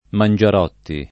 [ man J ar 0 tti ]